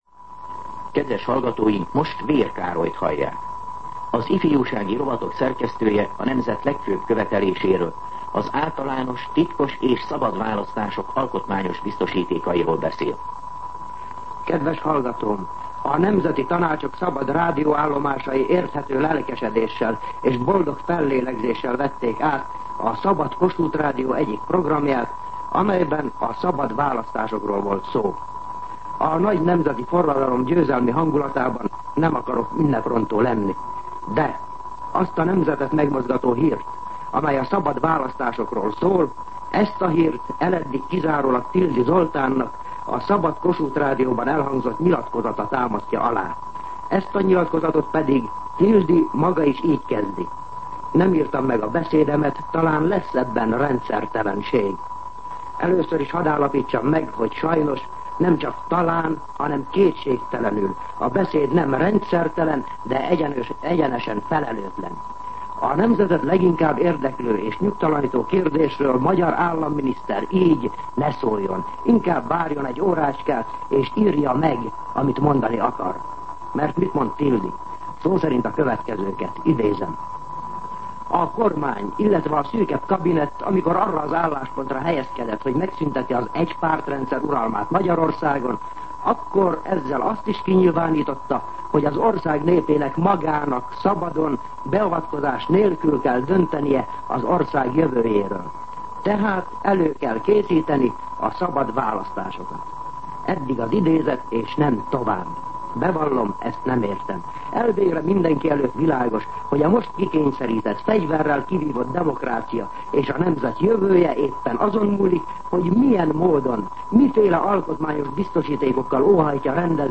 Szignál